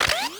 Added more sound effects.
MOTRSrvo_Plasma Rifle Arm_01_SFRMS_SCIWPNS.wav